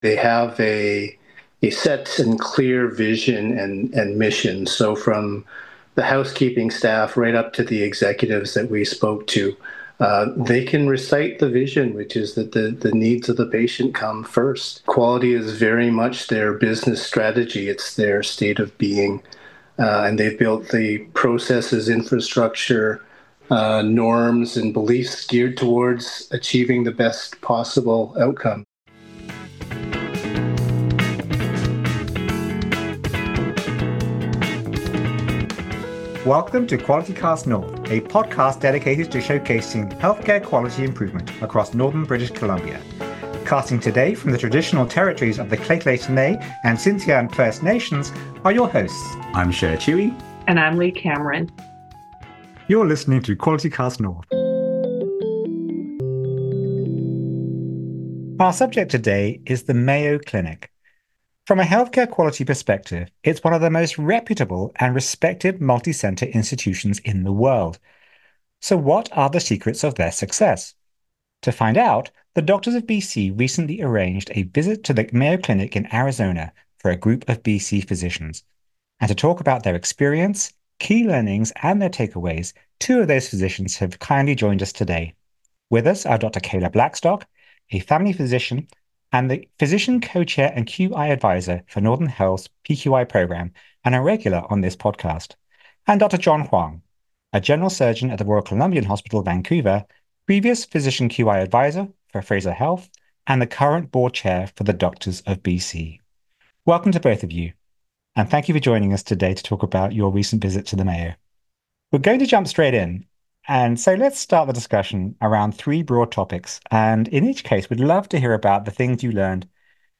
The Qualitycast North podcast showcases Northern British Columbian physicians and healthcare workers and their work in improving the quality of healthcare where they live. In an interview format